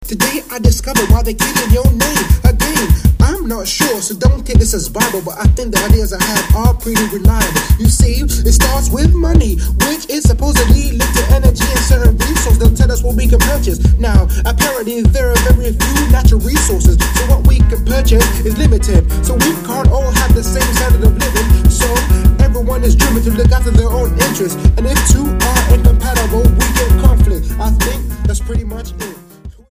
STYLE: World
If you're up for some African hip-hop this is for you.